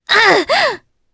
Priscilla-Vox_Damage_jp_03.wav